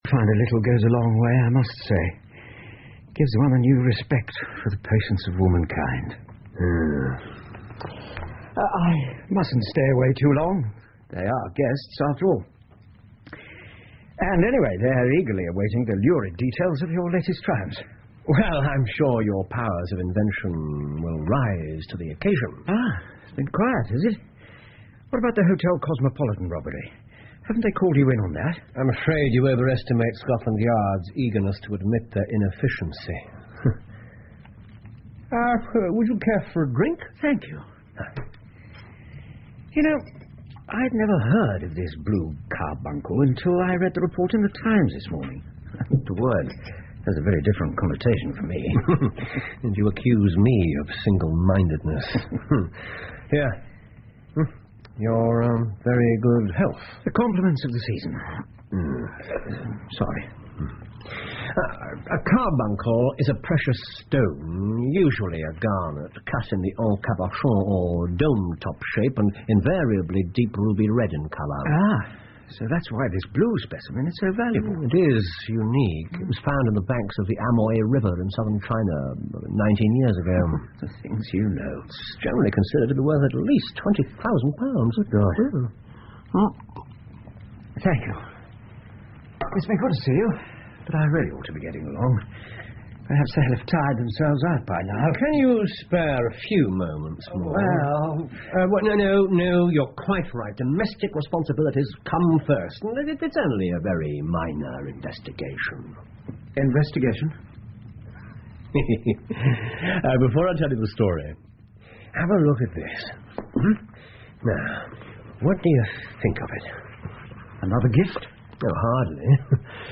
福尔摩斯广播剧 The Blue Carbuncle 3 听力文件下载—在线英语听力室